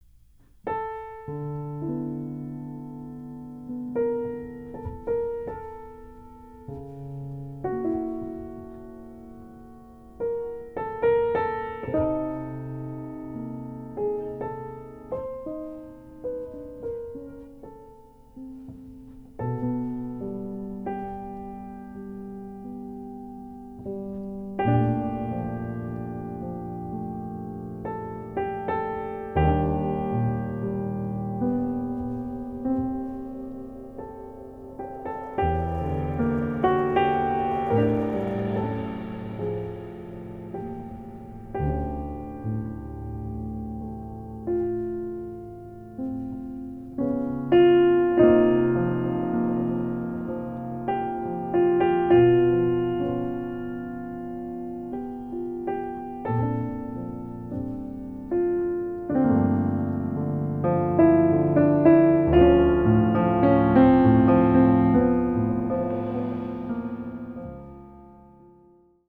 とても息の合ったアンサンブルを聴かせているのだ。
1日目のピアノトリオの録音が終わったところに、